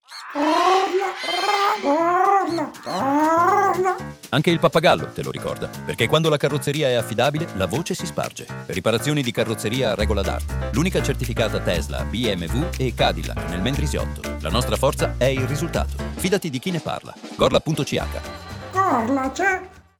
Spot radio CARROZZERIA
Lo stesso approccio è stato adattato alla radio con spot pensati per Radio 3i, dove il suono e la voce del pappagallo renderanno immediatamente riconoscibile il messaggio anche senza immagini.